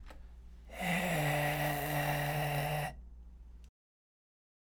NGのパーの声（仮声帯などが介入したまま強引に出してる